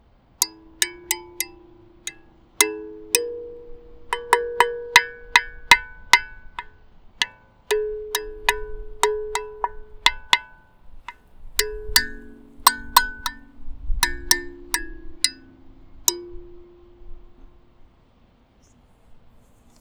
우주_칼림바1.wav